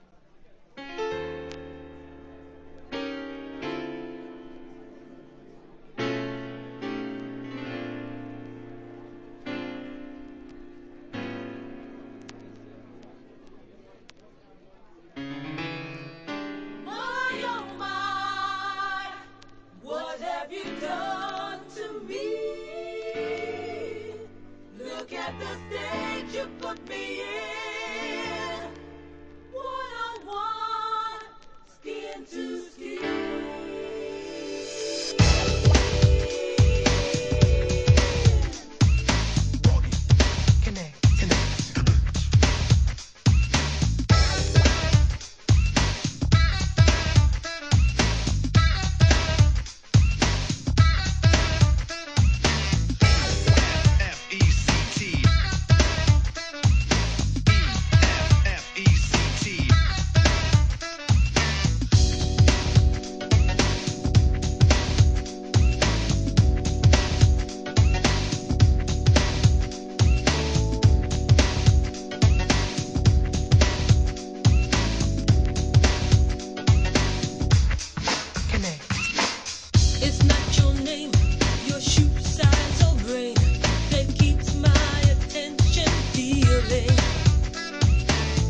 HIP HOP/R&B
タイトに跳ねたビートに軽快なヴォーカル・ワークで聴かせる1992年NEW JACK SWING!